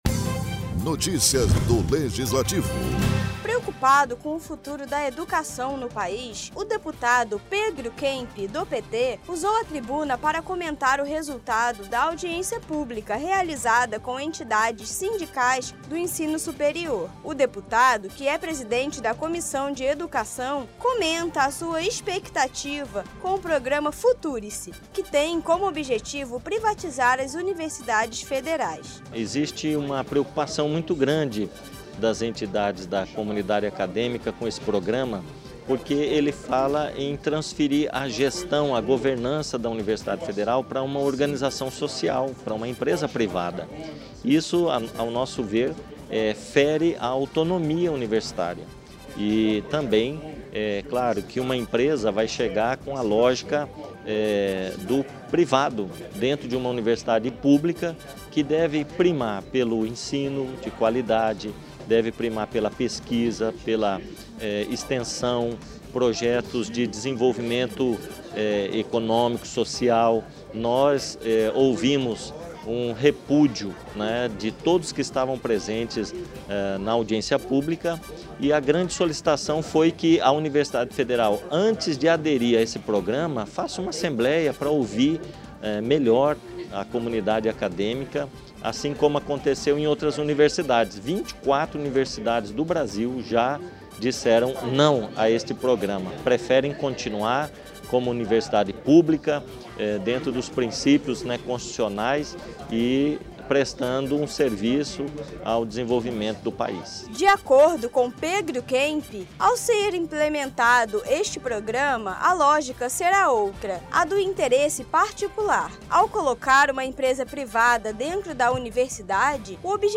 O deputado Pedro Kemp, do PT usou a tribuna na sessão plenária, para falar dos impactos do projeto Future-se, que foi tema de audiência pública realizada com entidades sindicais do ensino superior no Legislativo.